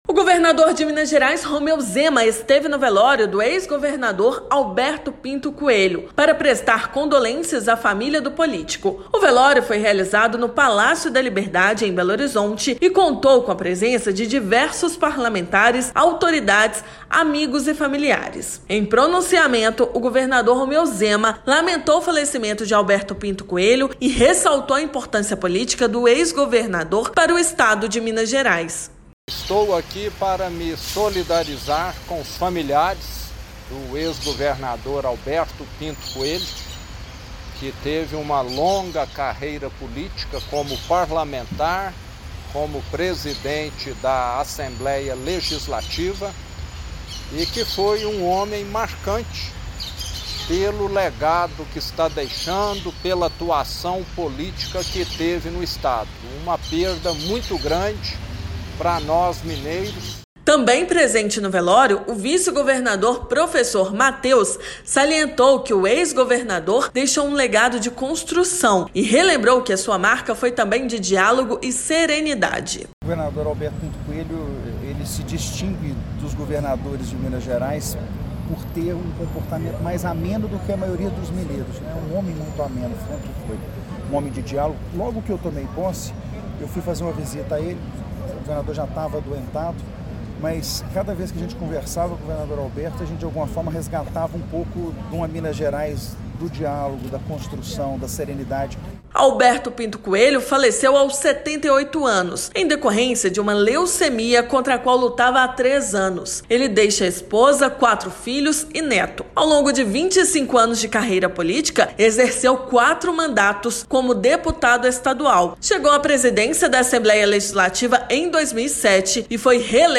Velório foi realizado no Palácio da Liberdade e sepultamento será na manhã desta terça-feira (21/11). Ouça matéria de rádio.